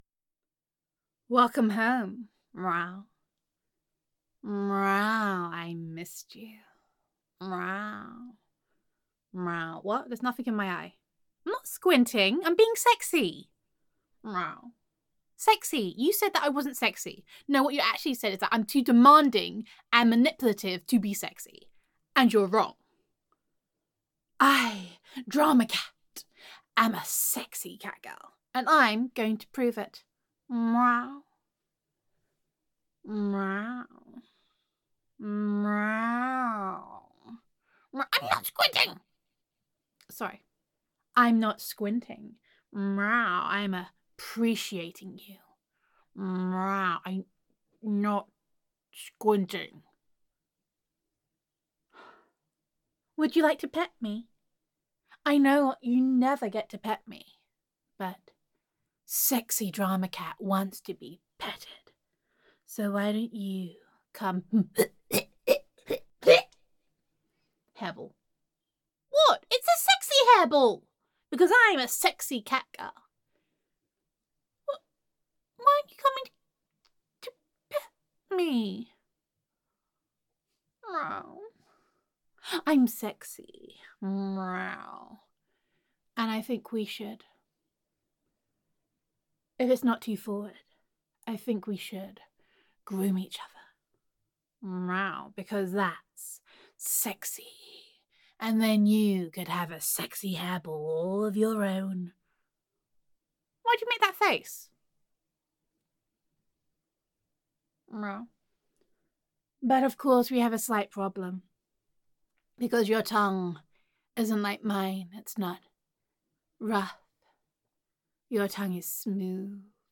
[F4A] Not All Cat Girls Are Sexy [Sometimes a Cat Is Simply a Cat][Drama Cat][Cattitude][Gender Neutral][Troublesome Neko Roleplay]